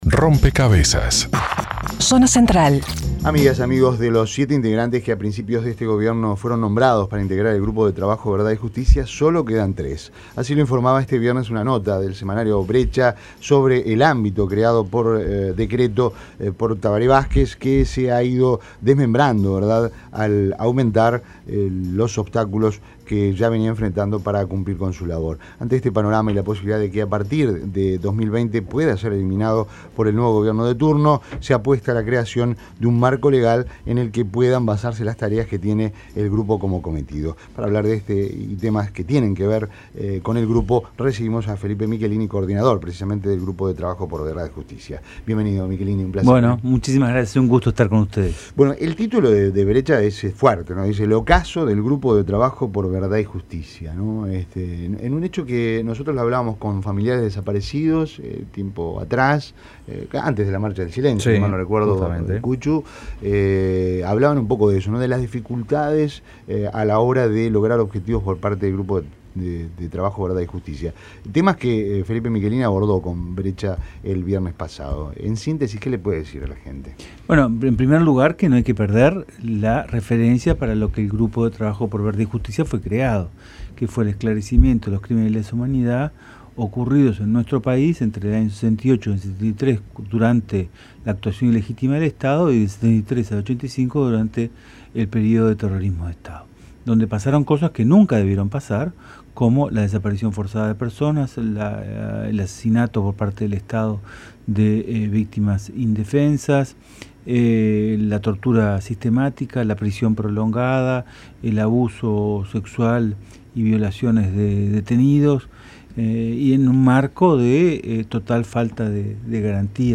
Entrevistado en Rompkzas, Felipe Michelini habló sobre los obstáculos que ha enfrentado el Grupo de Trabajo sobre Verdad y Justicia, del que es coordinador, que ha sufrido varias deserciones en los últimos tiempos.